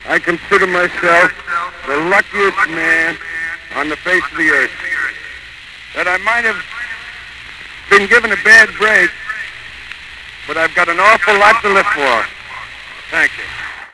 Premere sulla foto per ascoltare la voce di Lou Gehrig nel suo discorso d'addio